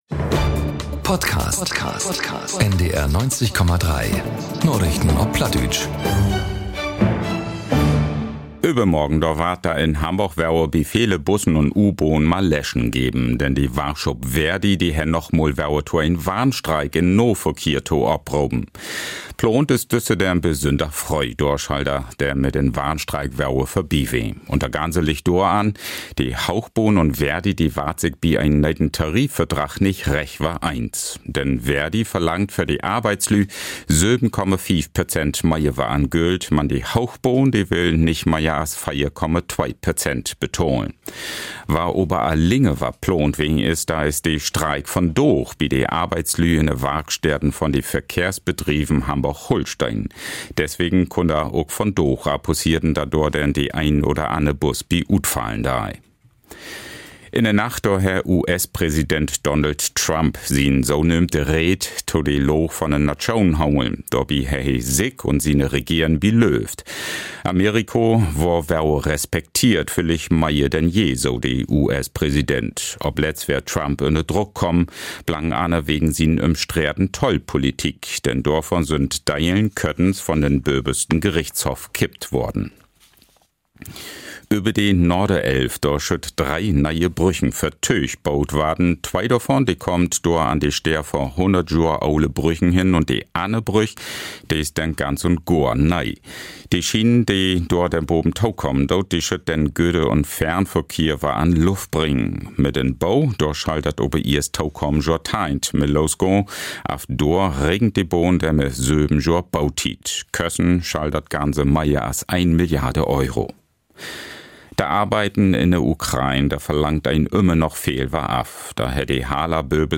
Narichten op Platt 25.02.2026 ~ Narichten op Platt - Plattdeutsche Nachrichten Podcast